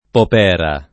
[ pop $ ra ]